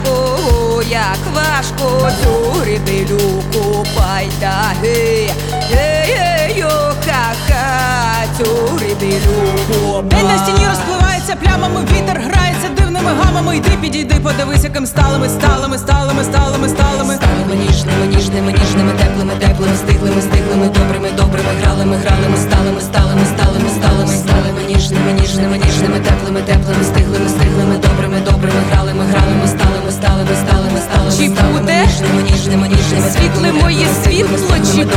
Alternative Classical Avant-Garde
Жанр: Альтернатива / Классика / Украинские